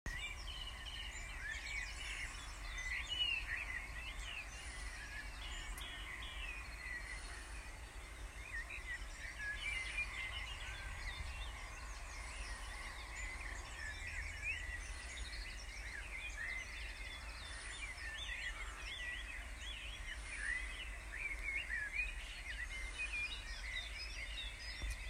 The month began with ‘Bird Song Day’: time to get up at 03.00 in order to listen to the Dawn chorus. I was rewarded with a beautiful sunrise preceded by fine bird song:
In terms of birds, the female chooses the male with the loudest song, so they are singing for all they are worth. If you listen carefully, there is a cuckoo on the background here.
New-Forest-National-Park-9.m4a